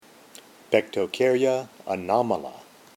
Pronunciation/Pronunciación:
Pec-to-cár-y-a a-nó-ma-la